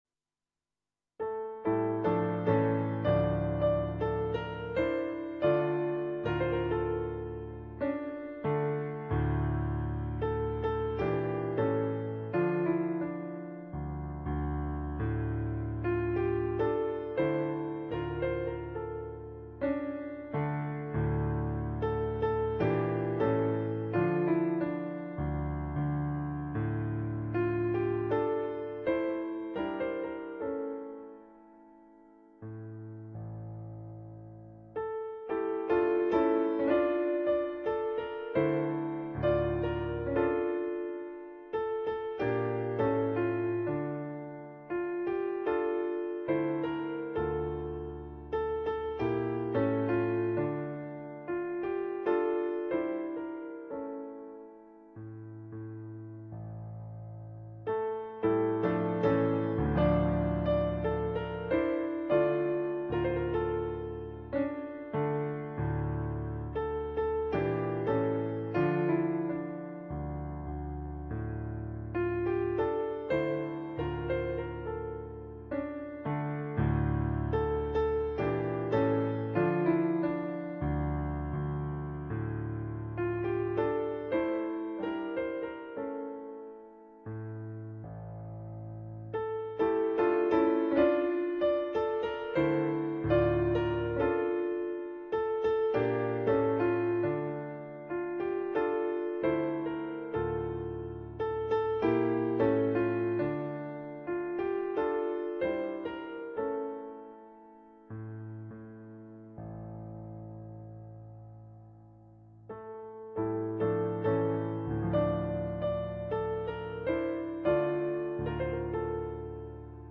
for Solo Piano
on Yamaha digital pianos.